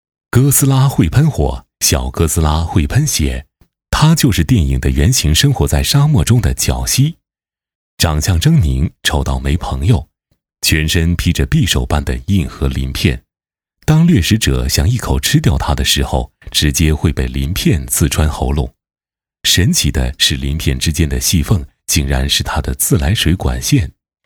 男22号配音师
8年从业经验，声音庄重、浑厚、大气。
代表作品 Nice voices 专题片 广告 旁白 专题片-男22-柒姑娘.mp3 复制链接 下载 专题片-男22-康精灵医疗.mp3 复制链接 下载 专题片-男22-崆峒山.mp3 复制链接 下载 专题片-男22-环境治理整治.mp3 复制链接 下载 专题片-男22-病毒.mp3 复制链接 下载